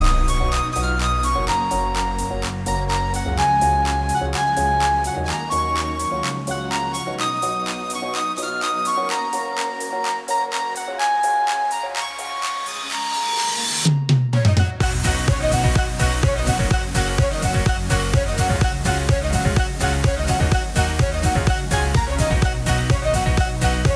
Minus Main Guitar Pop (2010s) 2:55 Buy £1.50